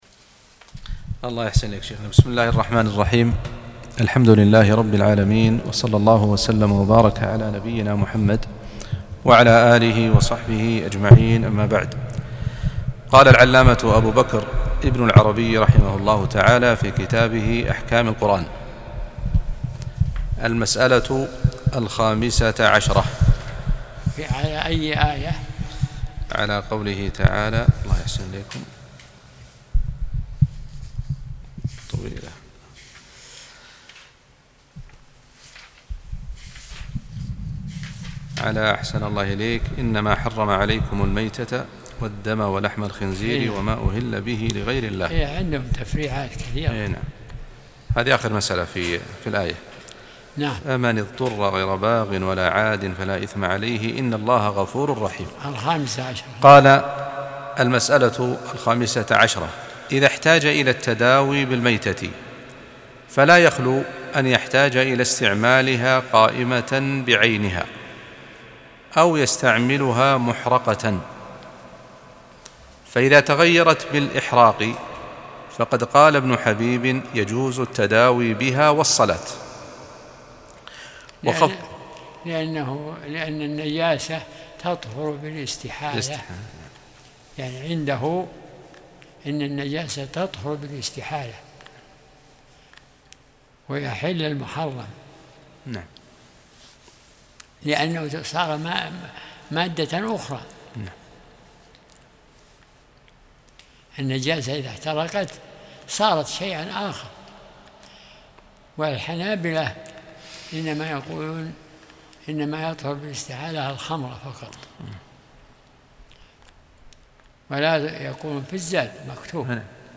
درس الأحد 33